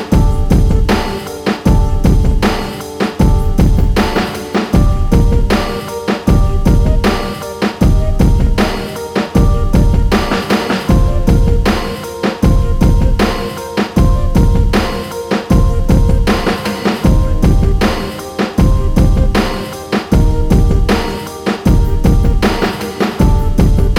No Lead Female R'n'B / Hip Hop 4:46 Buy £1.50